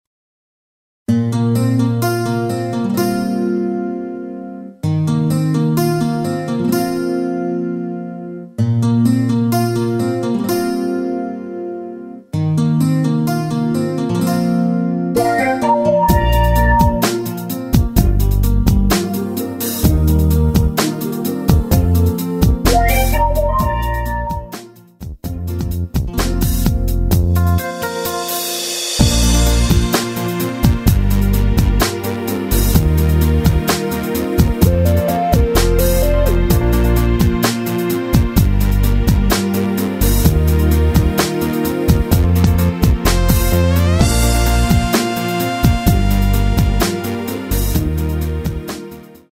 원키에서(+3) 올린 MR입니다.
Bb
앞부분30초, 뒷부분30초씩 편집해서 올려 드리고 있습니다.